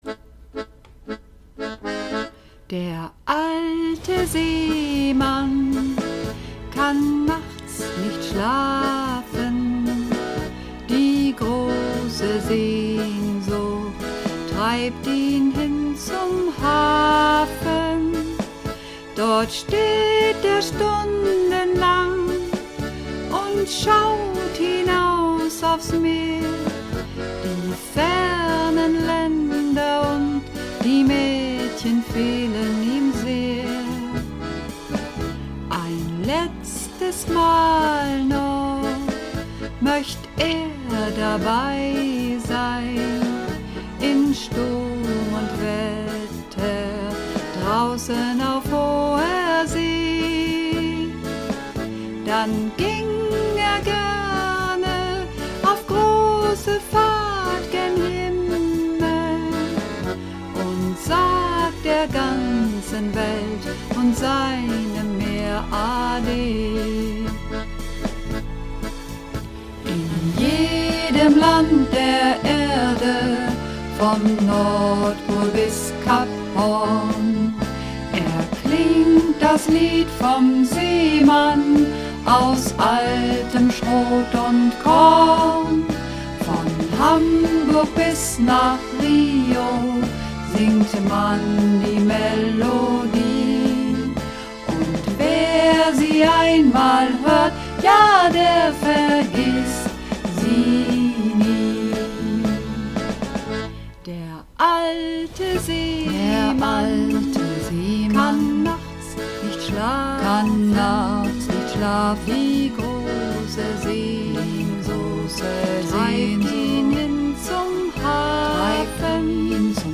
Übungsaufnahmen - Der alte Seemann
Der alte Seemann (Sopran)
Der_alte_Seemann__3_Sopran.mp3